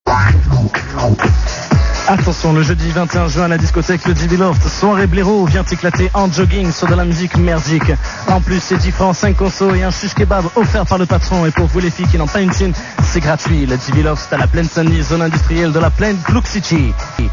Fausse Pubs : Le Débiloft